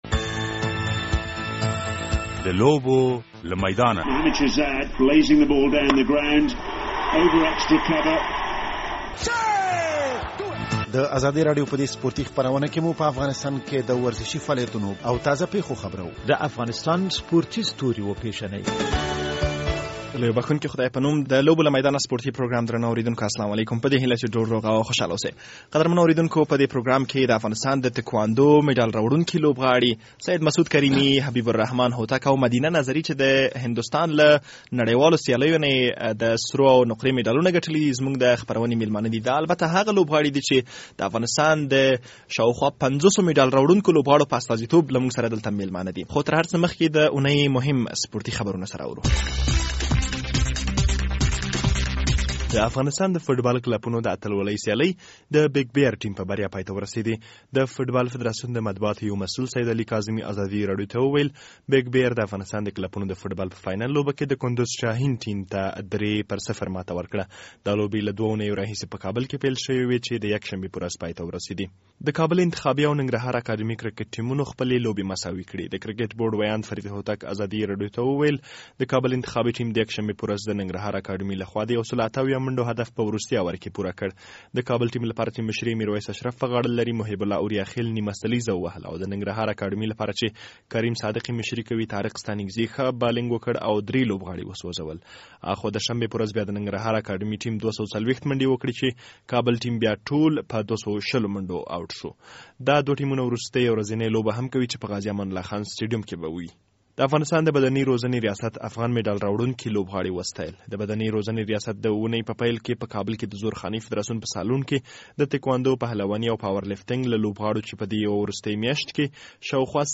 د لوبوله میدانه سپورټي پروګرام دا ځل د تکواندو د اتلانو کوربه دی.
په دې پروګرام کې د هندوستان د تکواندو له نړېوالو لوبونه د شاوخوا پنځوسو لوبغاړو په استازیتوب درې اتلان د ازادۍ راډیو میلمانه دي او له دوی سره د د دوی د لاسته راوړنو او سیالیو په اړه خبرې شوي.